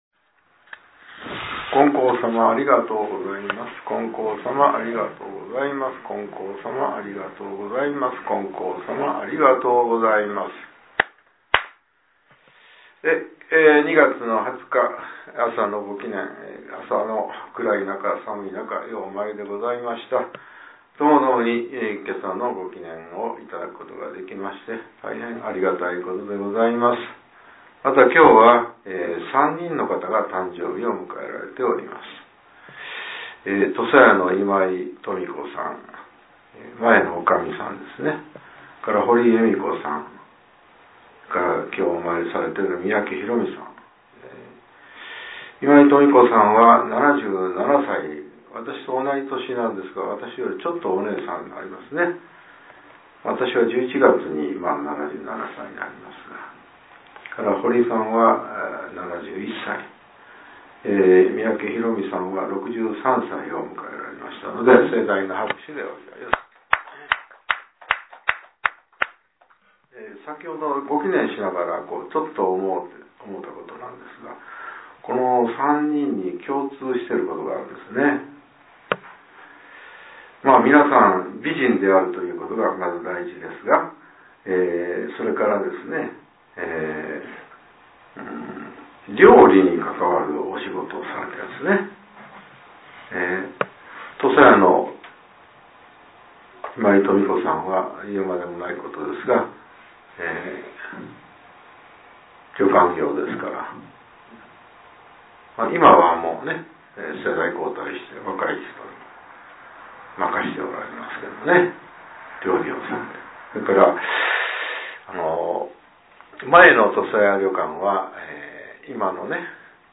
令和７年２月２０日（朝）のお話が、音声ブログとして更新されています。